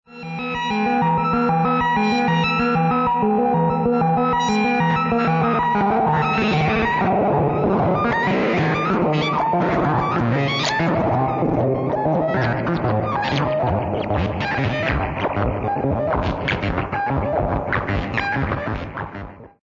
This control adds resistance to the powersource, making the modules unstable
SD_seq-stab.mp3